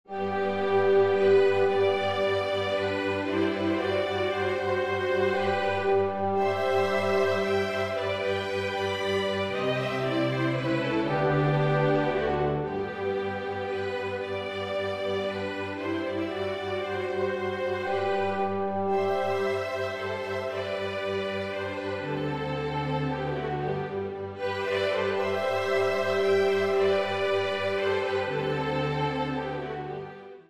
Orchester-Sound